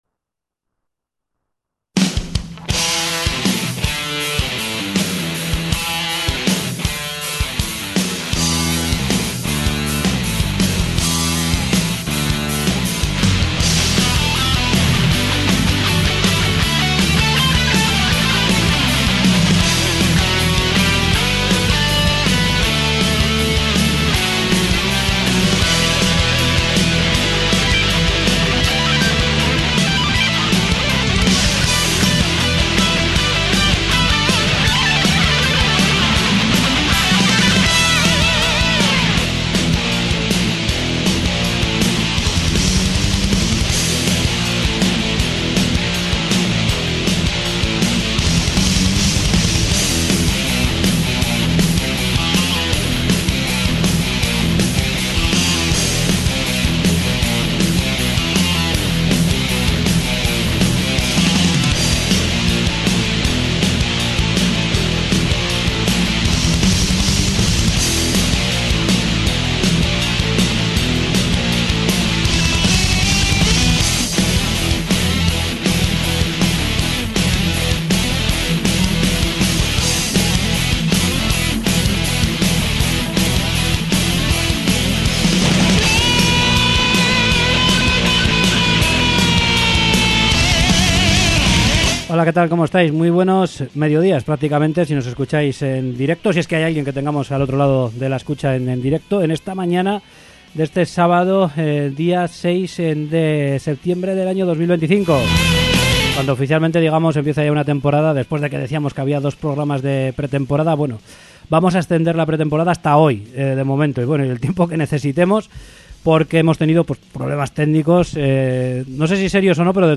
Entrevista con Corazones Eléctricos